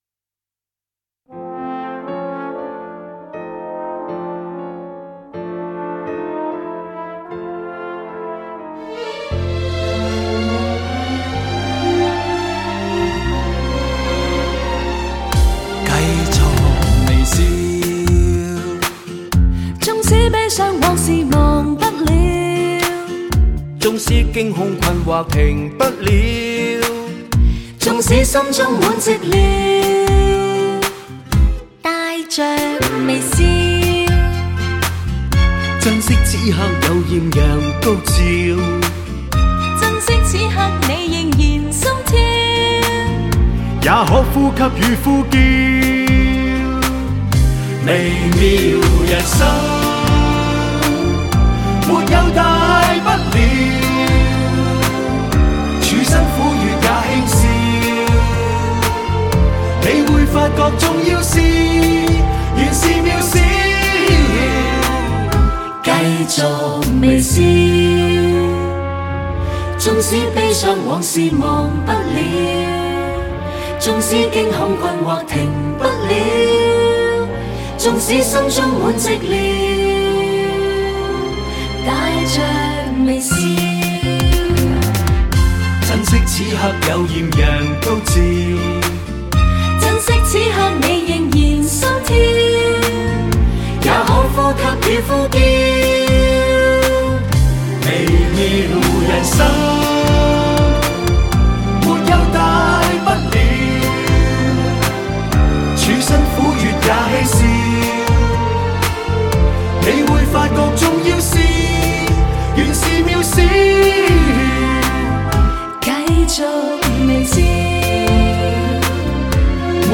HI-FI 原装德国24K金碟